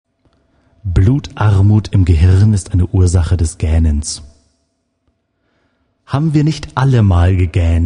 gaehnen.MP3